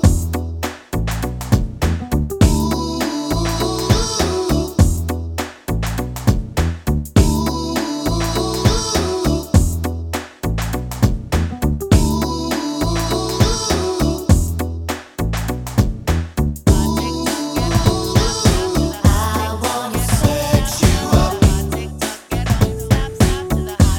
no Backing Vocals R'n'B